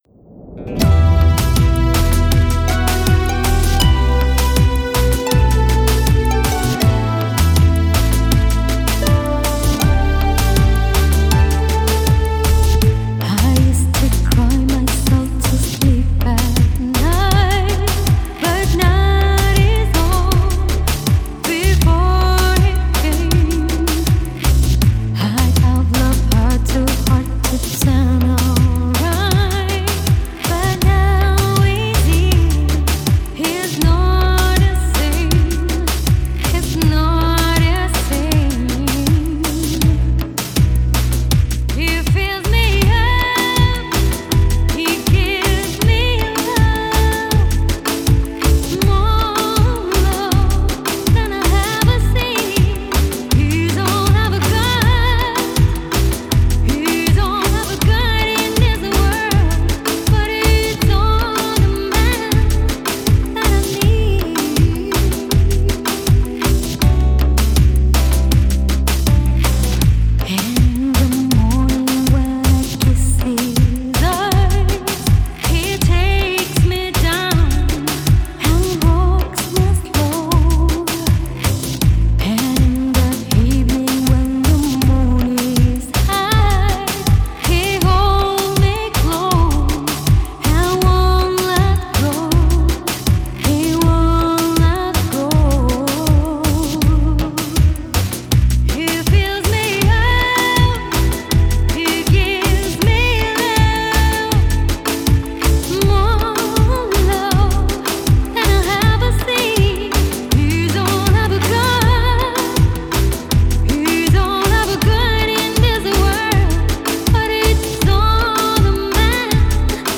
reggaeton lento